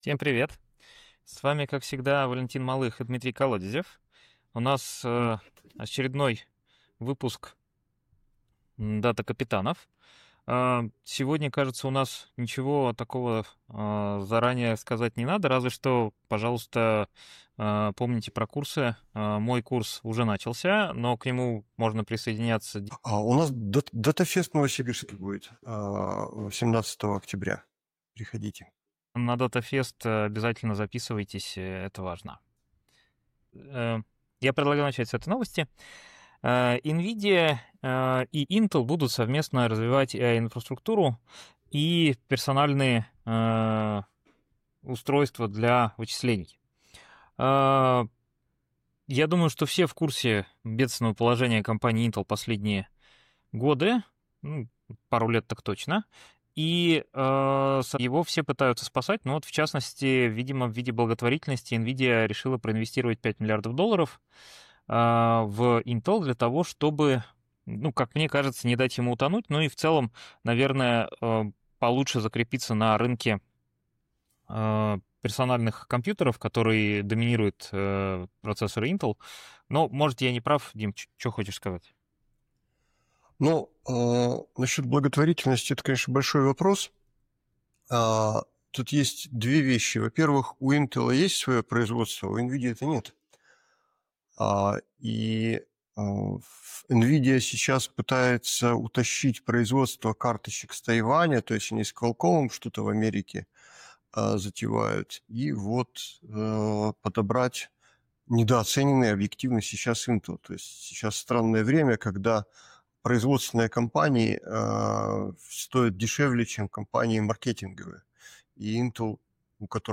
В этот раз вдвоем.